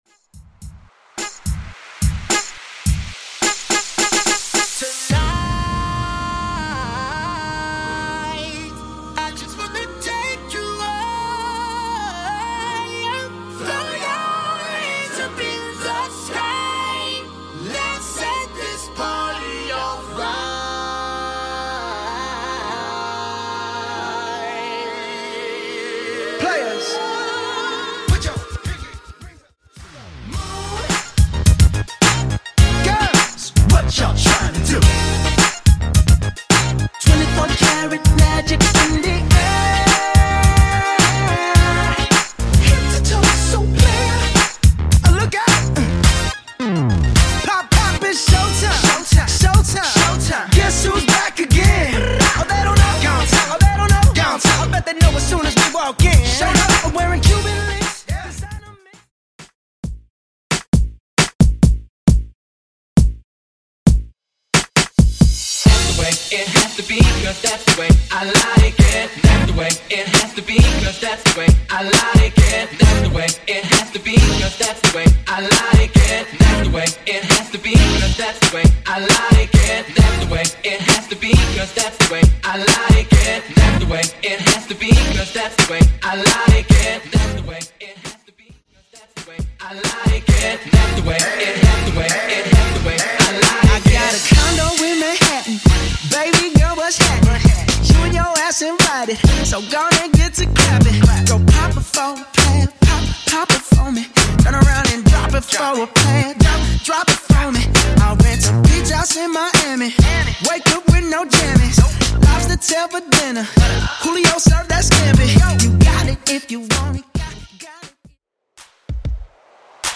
80s Halloween House Rework) 128 Bpm
80s Halloween Redrum
Halloween Melbourne Bounce